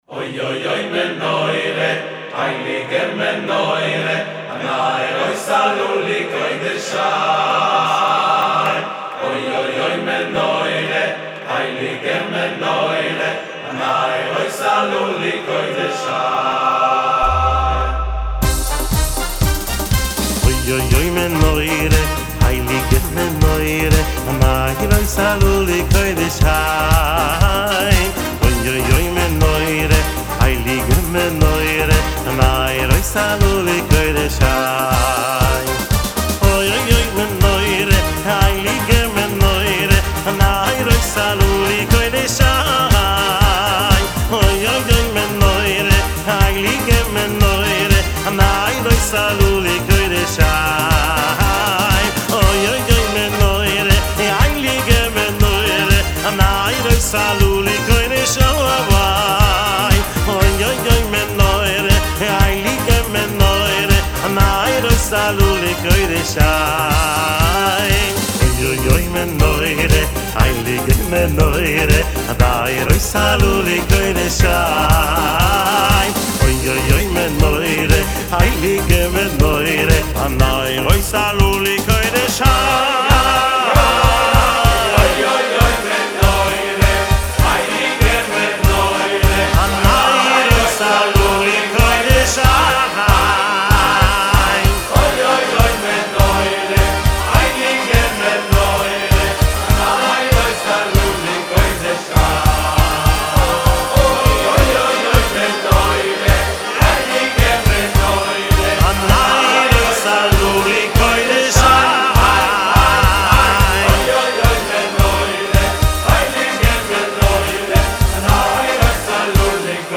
ביצוע חסידי אותנטי לניגון הידוע
נכנס לאולפן ומגיש ביצוע משלו ללהיט הגדול.